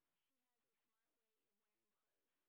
sp26_street_snr30.wav